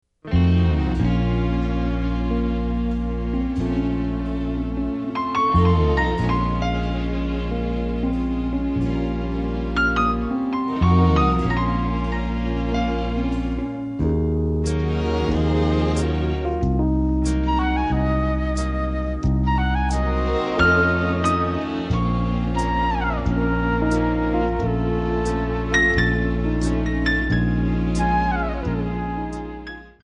Backing track files: Jazz/Big Band (222)